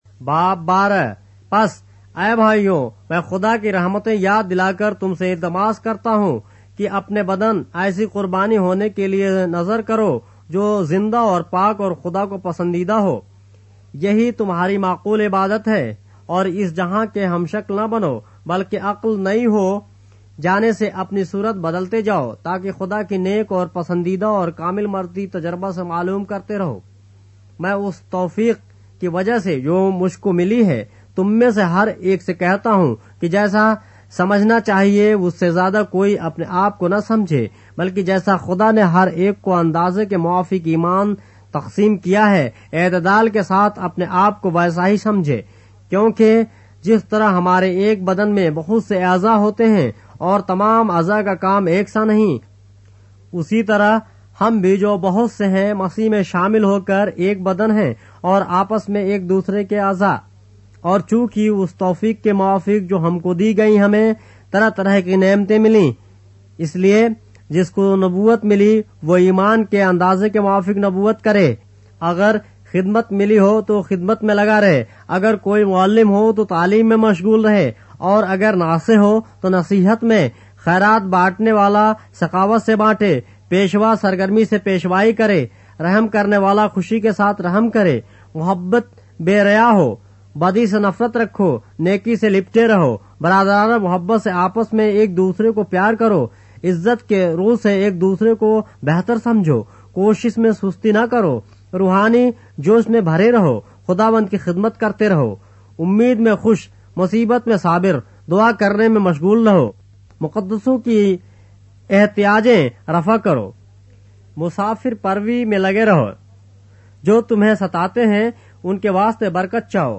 اردو بائبل کے باب - آڈیو روایت کے ساتھ - Romans, chapter 12 of the Holy Bible in Urdu